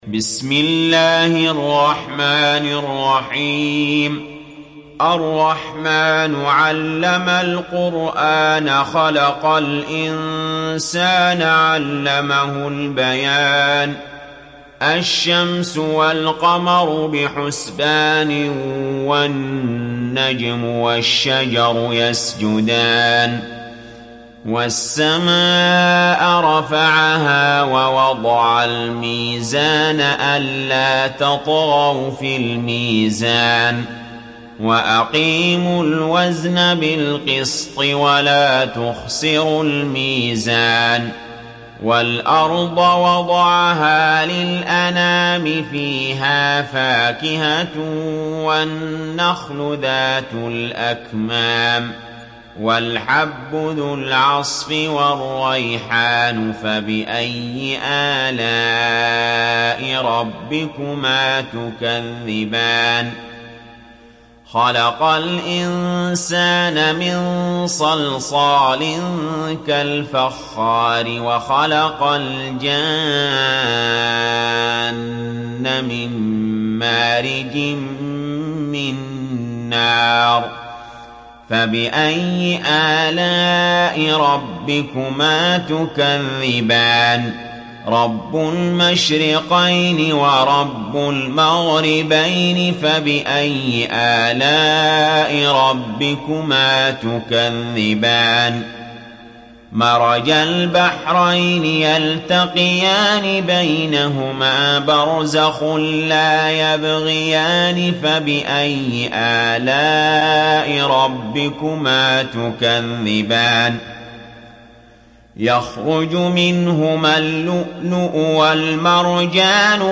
دانلود سوره الرحمن mp3 علي جابر روایت حفص از عاصم, قرآن را دانلود کنید و گوش کن mp3 ، لینک مستقیم کامل